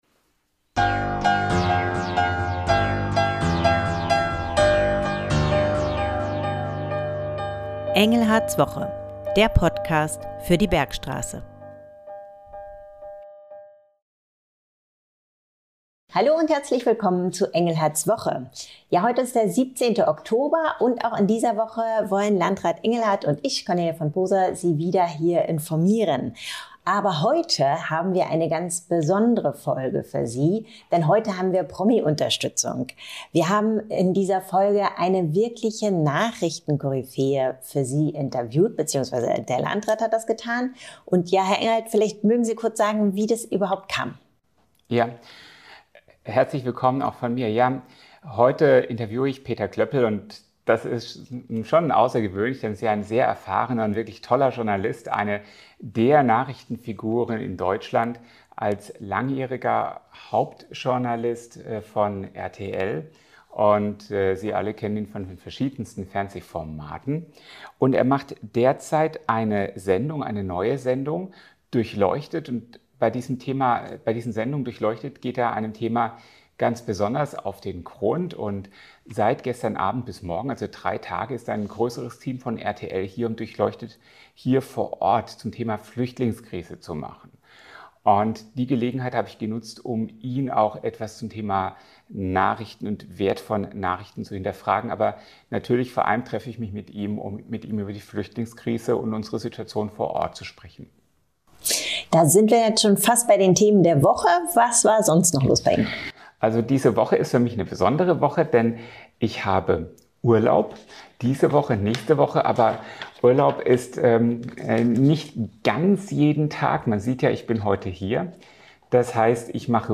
Schwerpunktthemen: Wochenrückblick Wie können wir gut informiert bleiben - in Zeiten von fake news und Informationsüberflutung? – Interview mit Peter Kloeppel Veranstaltungstipps